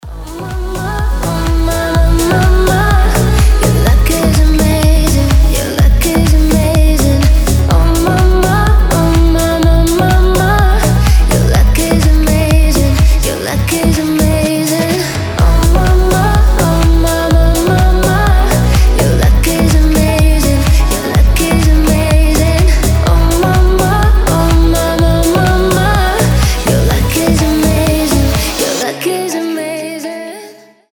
deep house
красивый женский голос